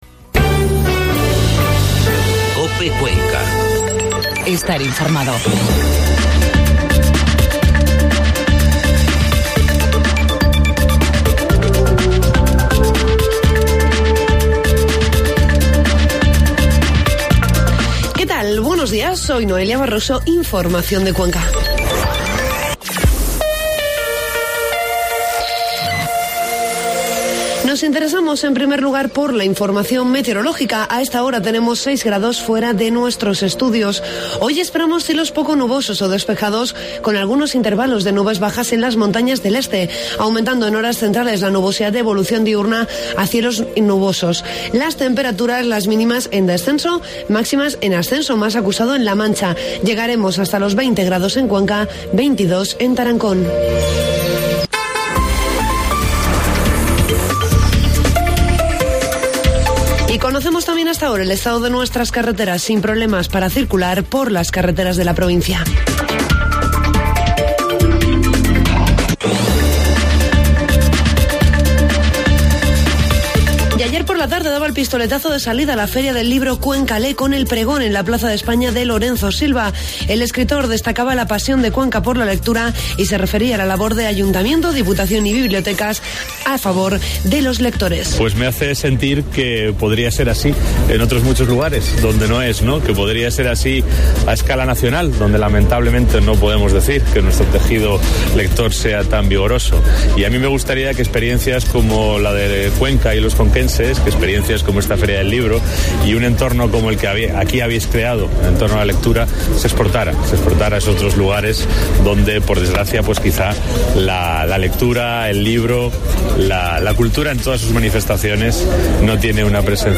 Informativo matinal COPE Cuenca 15 de mayo